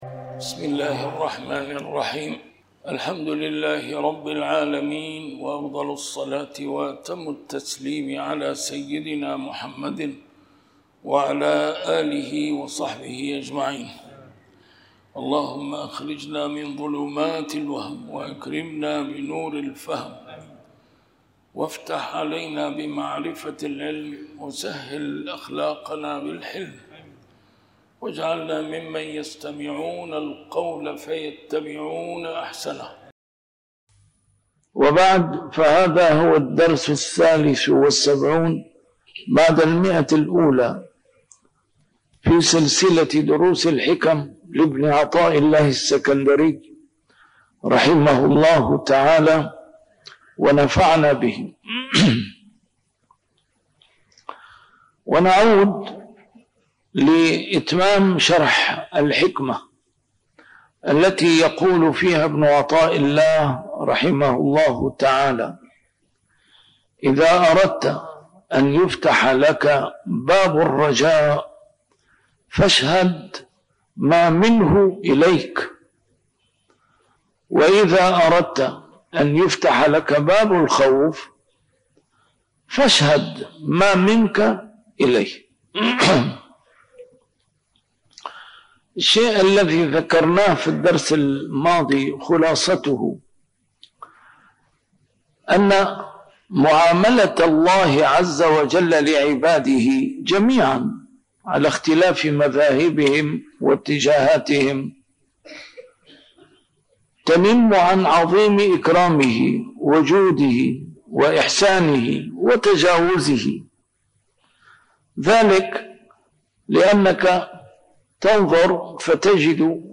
A MARTYR SCHOLAR: IMAM MUHAMMAD SAEED RAMADAN AL-BOUTI - الدروس العلمية - شرح الحكم العطائية - الدرس رقم 173 شرح الحكمة 149+150